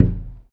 Glasshit.ogg